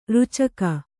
♪ rucaka